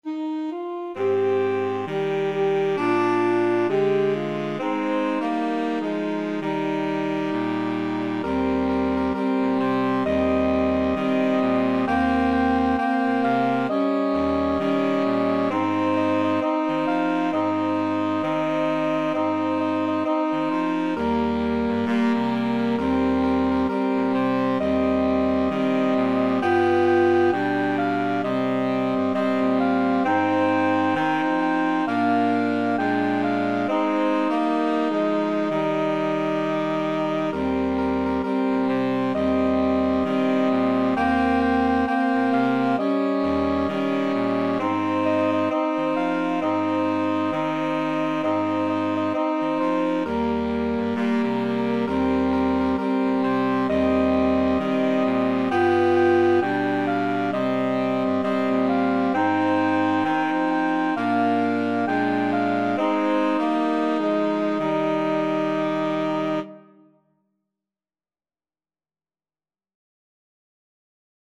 4/4 (View more 4/4 Music)
Saxophone Quartet  (View more Easy Saxophone Quartet Music)
Traditional (View more Traditional Saxophone Quartet Music)